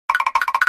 Added more SFX logic
walk.mp3